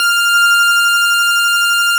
snes_synth_077.wav